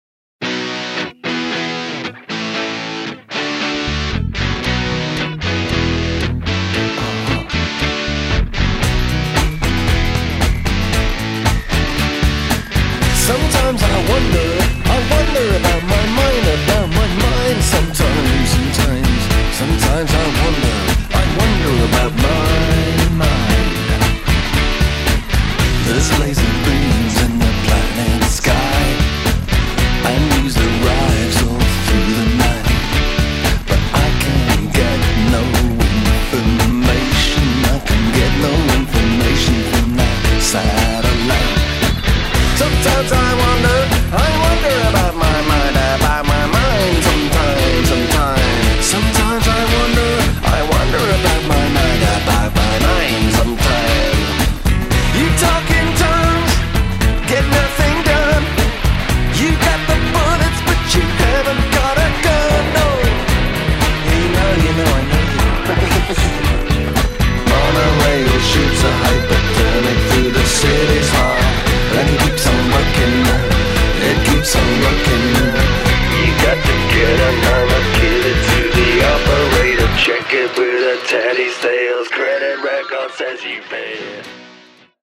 Asian-tinged psychedelic punk